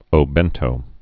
(ō-bĕntō)